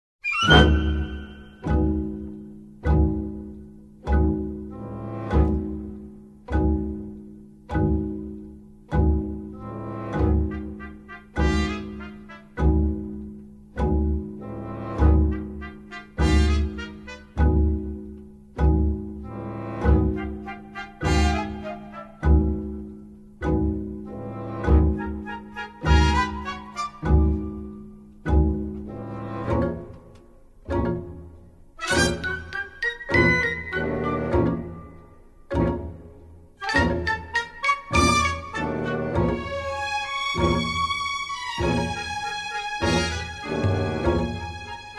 Utilizing exotic percussion and a talented flute soloist
was recorded by a non-union orchestra in Toronto